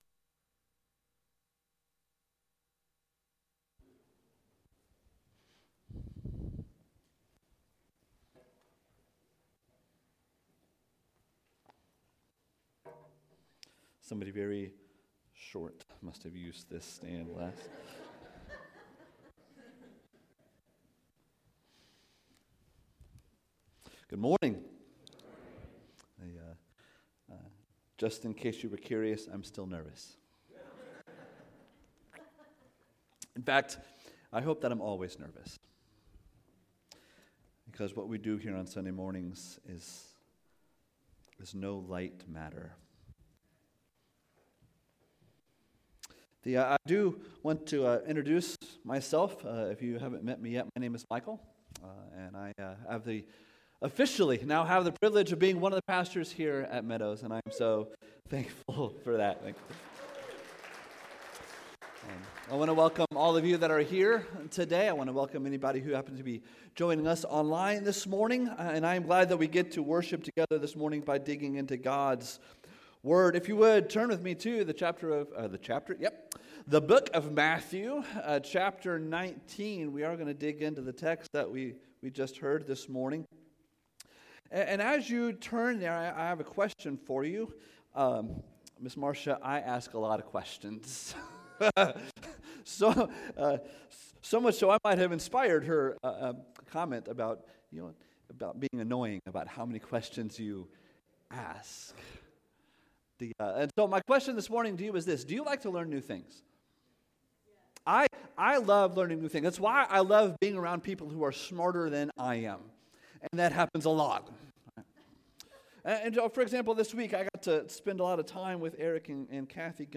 Sermons | Meadows Christian Fellowship